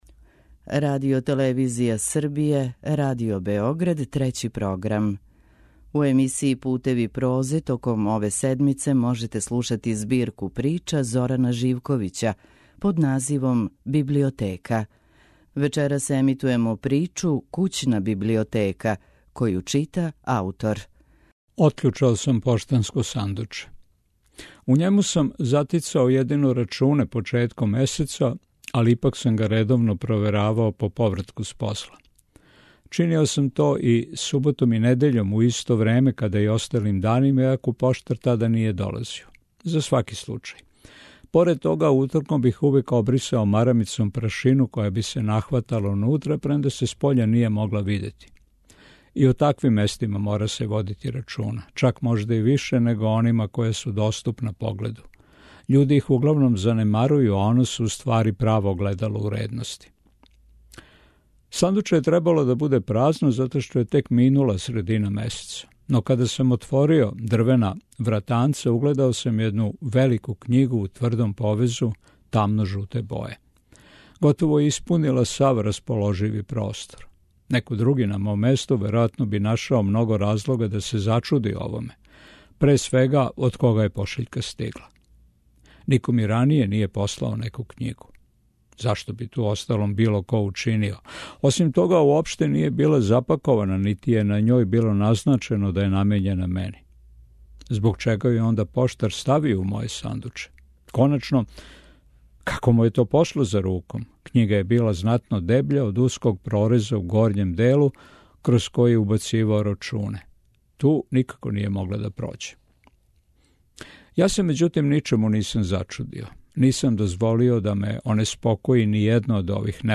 У емисији Путеви прозе, од понедељка, 3. до недеље, 9. октобра, можете слушати ново издање мозаичког романа Зорана Живковића „Библиотека” који ће читати аутор.
Књига за слушање